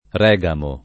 regamo [ r $g amo ]